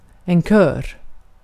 Ääntäminen
Ääntäminen Tuntematon aksentti: IPA: /kœːr/ IPA: /ɕœːr/ Haettu sana löytyi näillä lähdekielillä: ruotsi Käännös Ääninäyte Substantiivit 1. choir UK 2. singing group 3. chorus US Artikkeli: en .